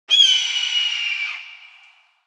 جلوه های صوتی
دانلود صدای بیابان 3 از ساعد نیوز با لینک مستقیم و کیفیت بالا